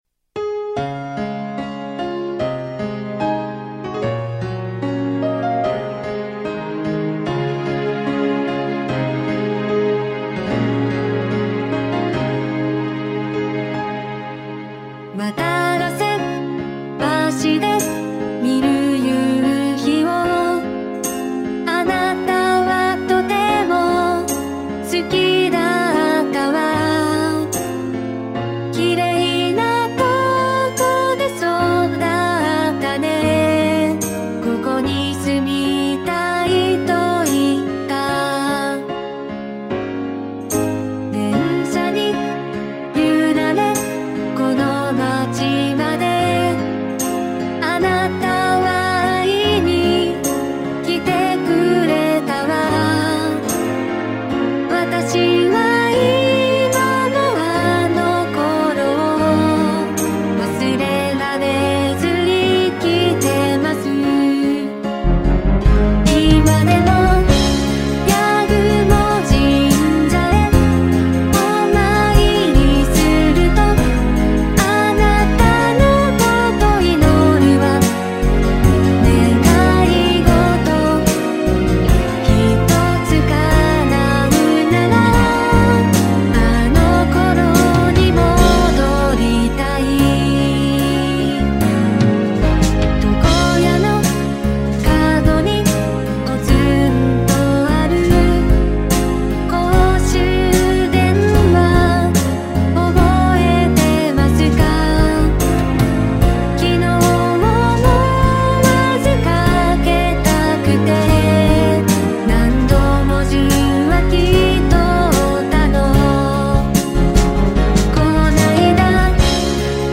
原曲のしっとりした感じが出てるでしょうか。